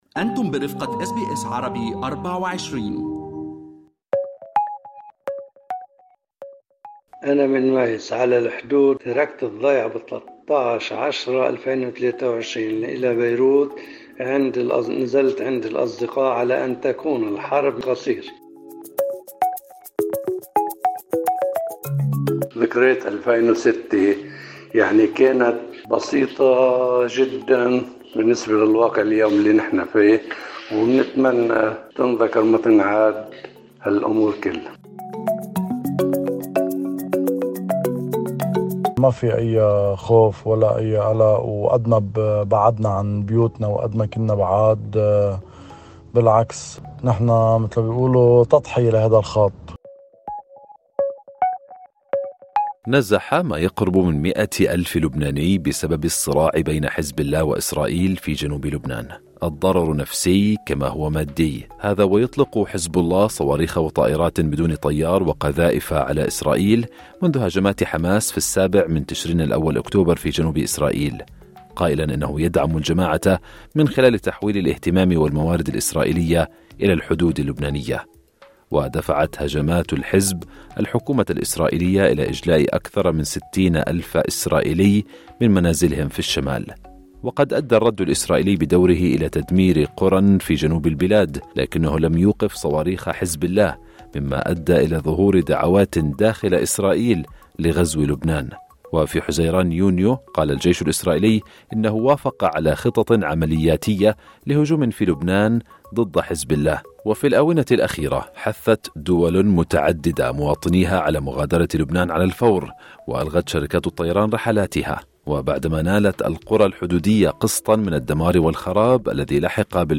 نزح أكثر من 100 ألف شخص في جنوب لبنان منذ اندلاع الأعمال القتالية، بحسب المنظمة الدولية للهجرة، بينما فر 60 ألف شخص في إسرائيل من منازلهم في الشمال. في هذا التقرير نسلط الضوء على مأساة النزوح في لبنان.